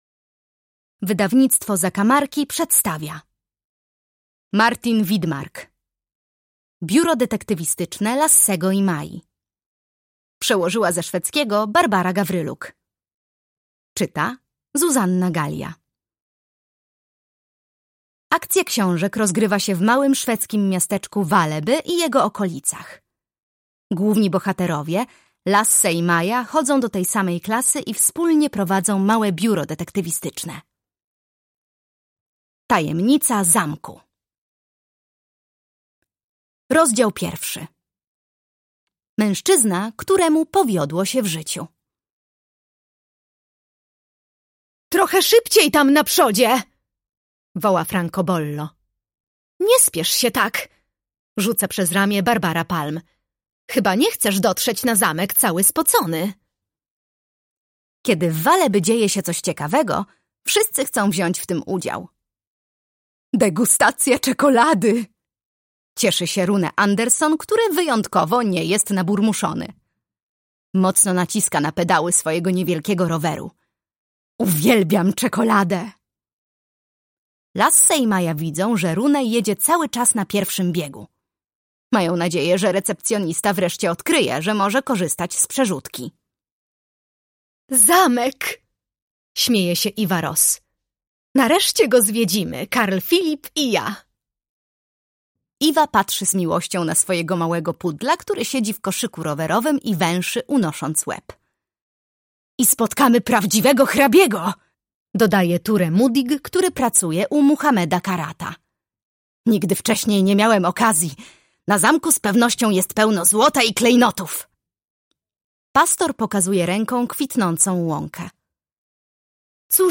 Tajemnica zamku - Martin Widmark - audiobook - Legimi online